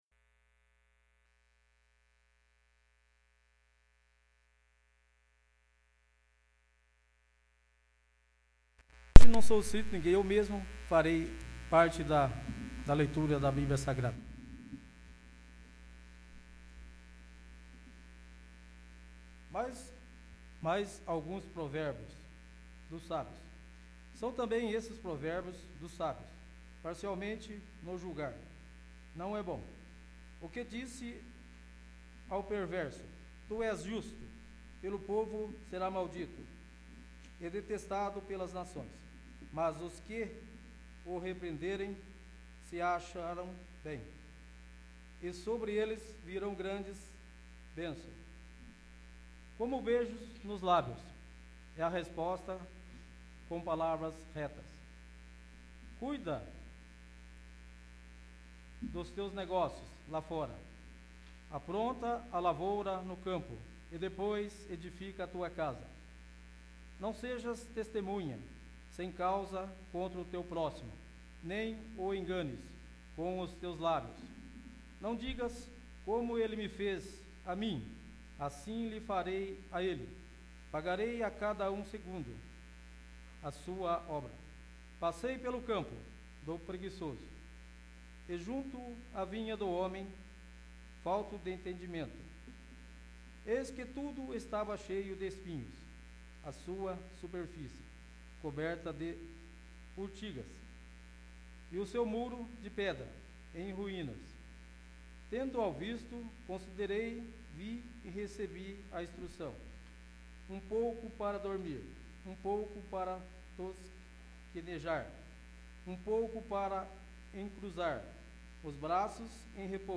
10º. Sessão Ordinária 18/04/2017
10º. Sessão Ordinária